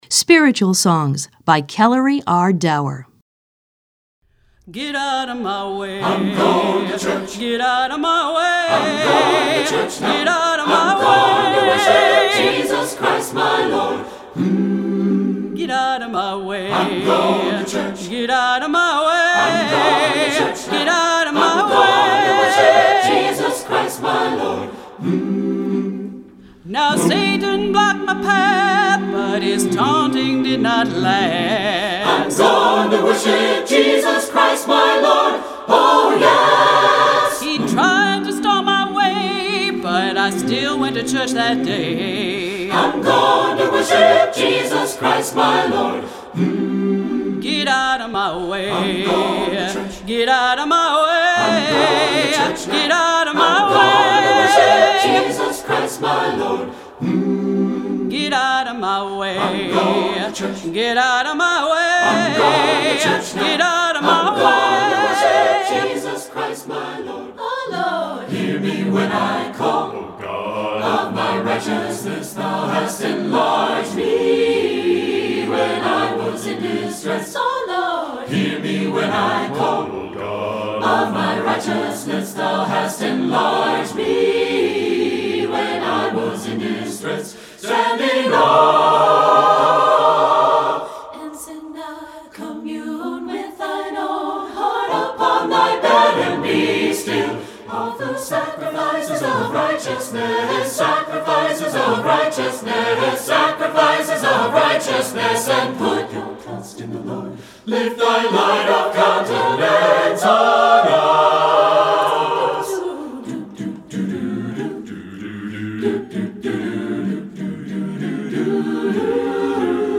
Choral Spiritual
SATB A Cap